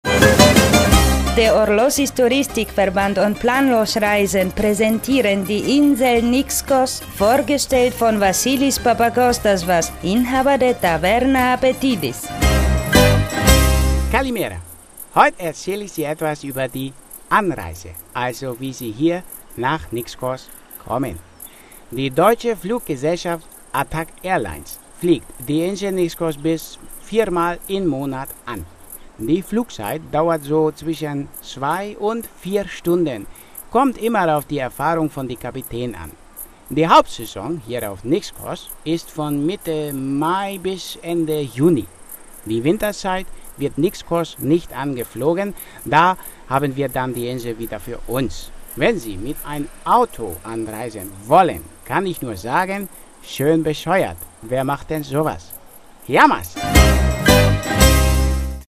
INSEL NIXKOS; Radiocomedy